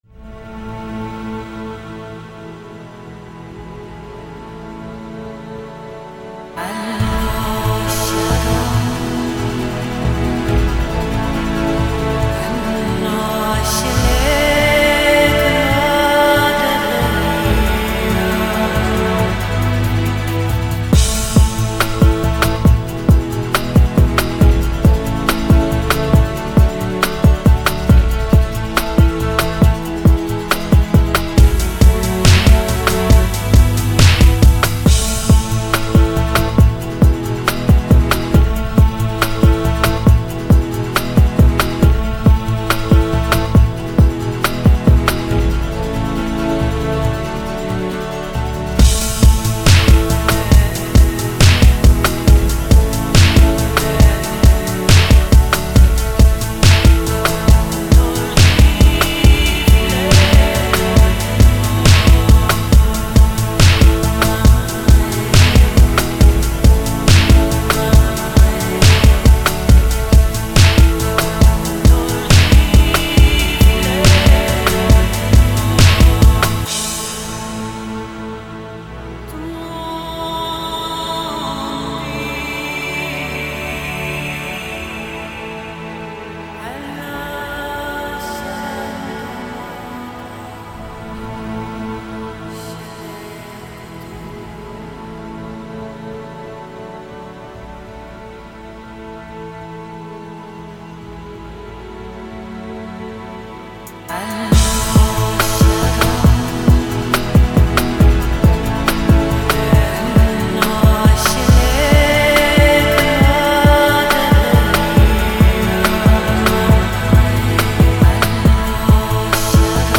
rmx